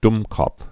(dmkôf, -kôpf, dŭm-)